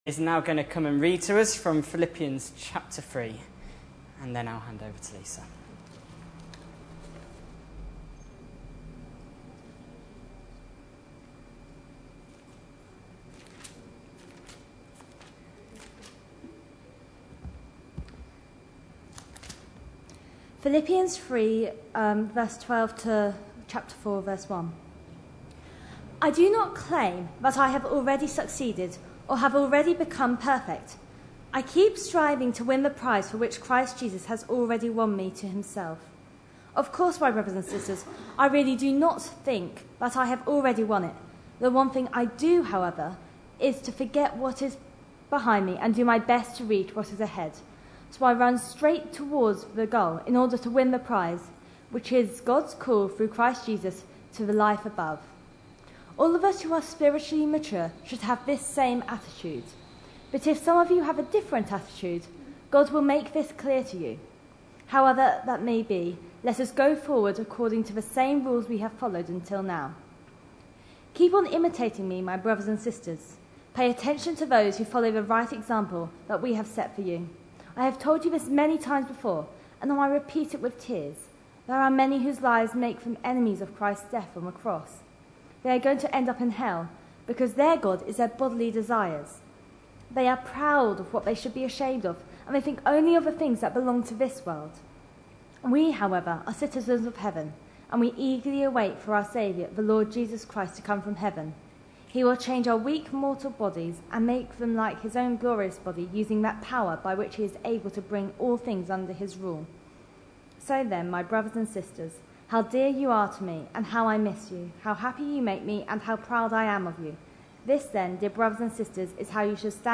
A sermon preached on 19th August, 2012, as part of our Olympic Ideals series.